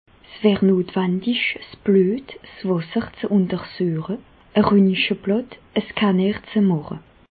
Bas Rhin
Ville Prononciation 67
Reichshoffen